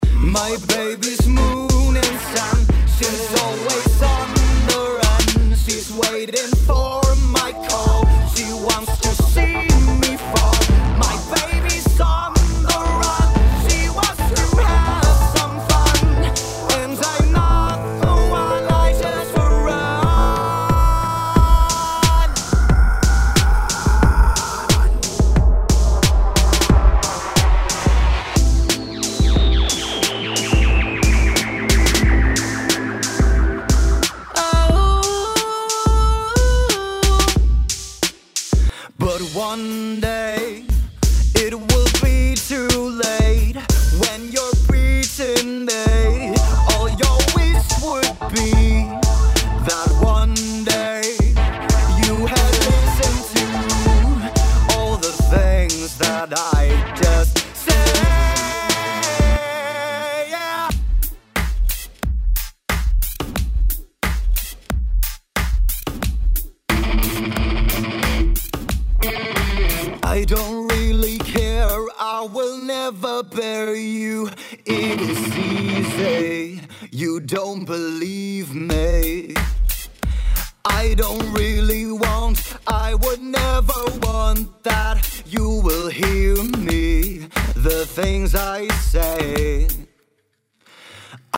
RockMetal
RockMetal.MP3